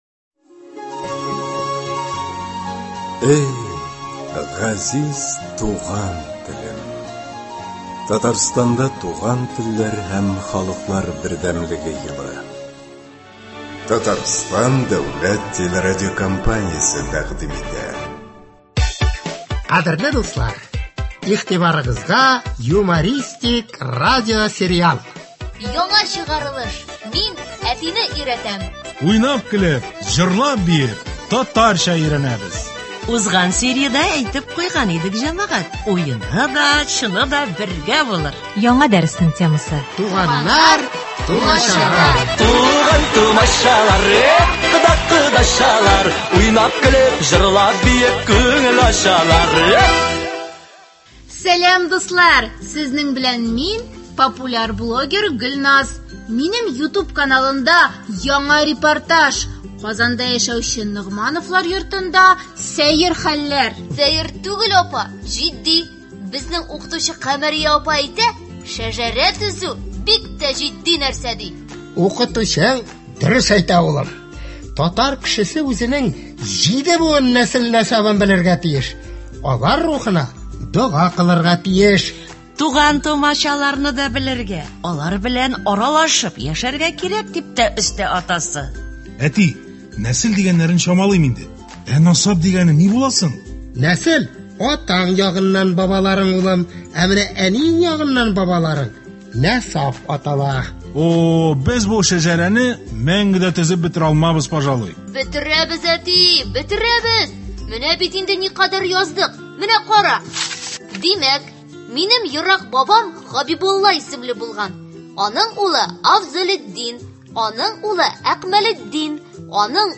Ул – “Мин әтине өйрәтәм” дип исемләнгән радиосериал. Кыска метражлы әлеге радиоспектакльләрдә туган телебезне бозып сөйләшү көлке бер хәл итеп күрсәтелә һәм сөйләмебездәге хата-кимчелекләрдән арыну юллары бәян ителә.